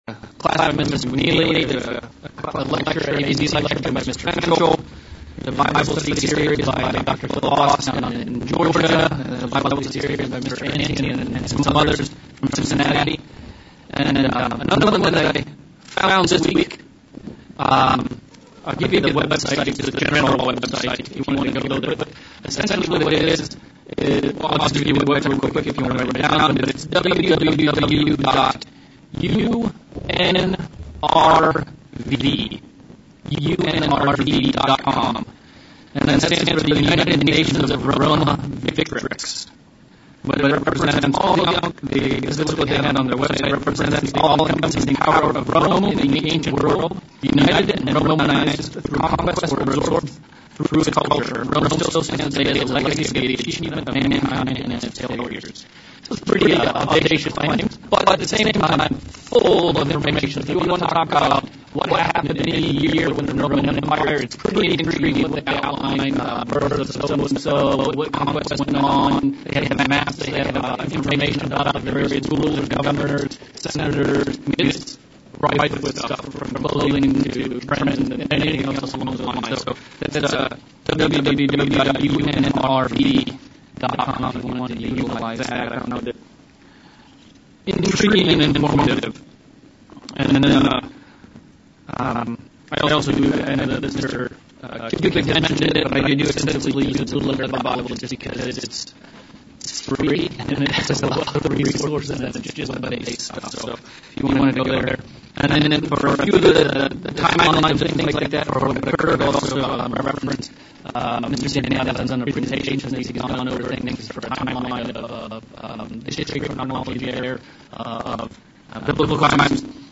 December Bible Study
Given in Central Illinois